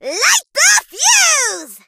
bonni_lead_vo_05.ogg